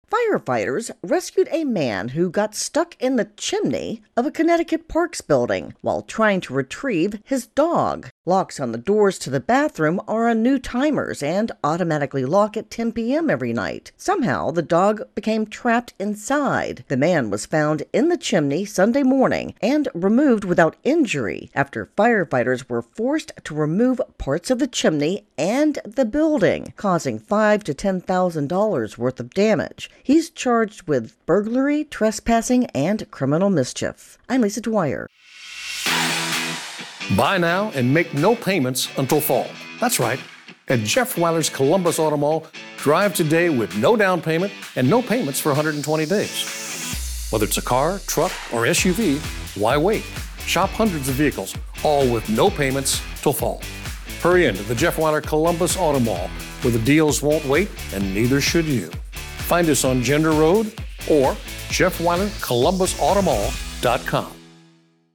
reports on a man who found himself in a rather tight spot over the weekend.